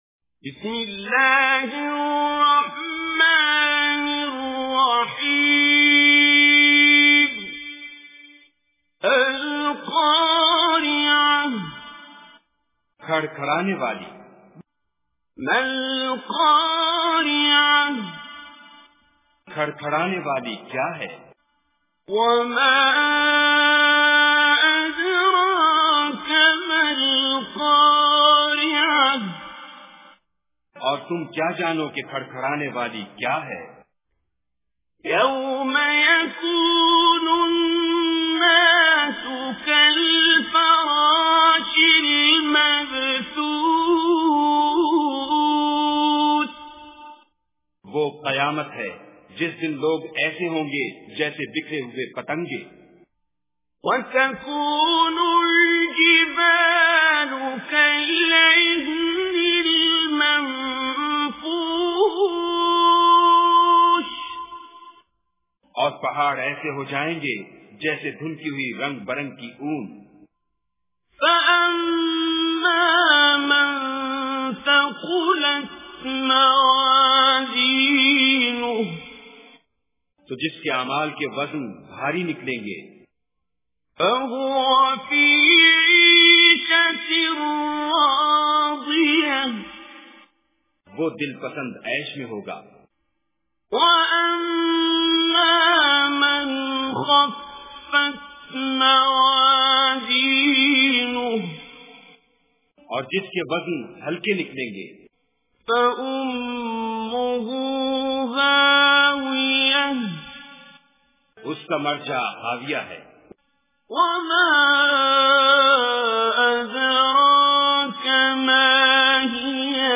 Surah Al-Qariah Recitation with Urdu Translation
Surah Al-Qariah is 101 chapter of Holy Quran. Listen online and download mp3 tilawat / recitation of Surah Al-Qariah in the beautiful voice of Qari Abdul Basit As Samad.